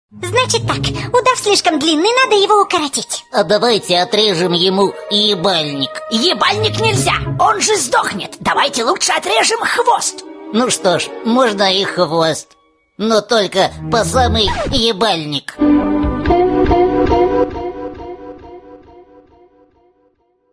• Качество: 128, Stereo
грубые